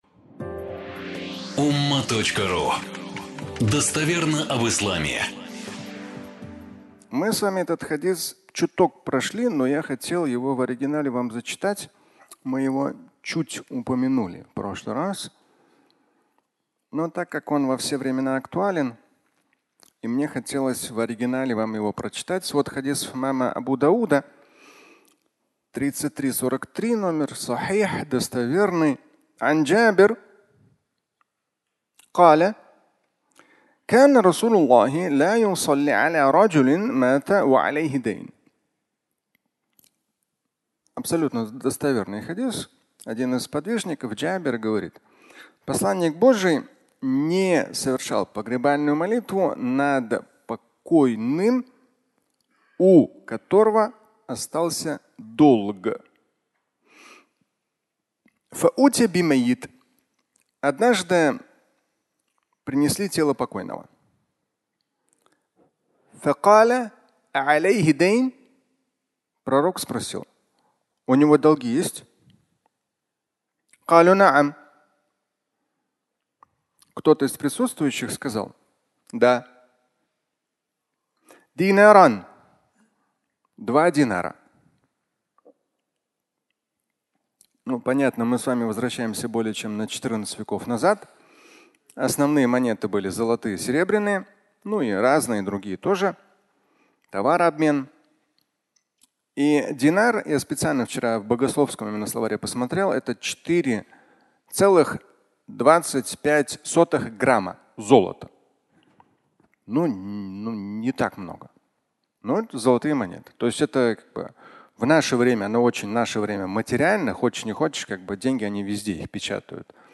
Заплачу за него (аудиолекция)